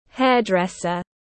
Hairdresser /ˈherdresər/
Hairdresser.mp3